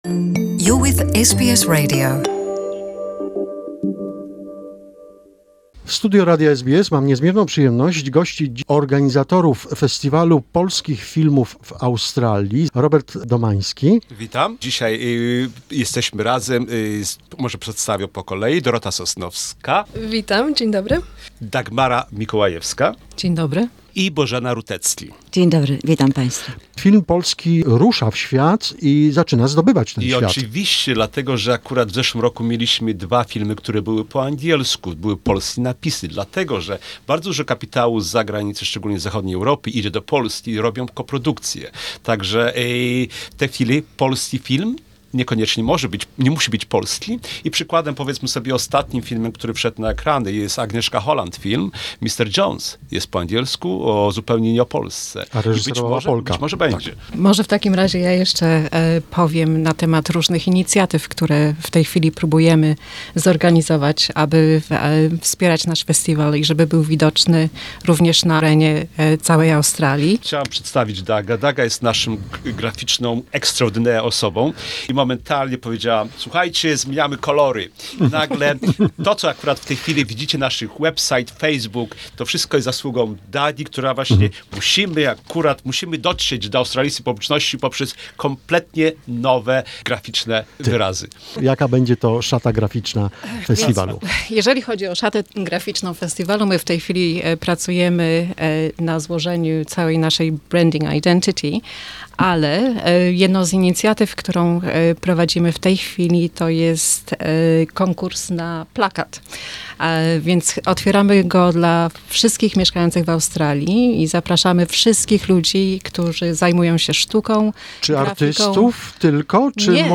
this is the second part of the interview.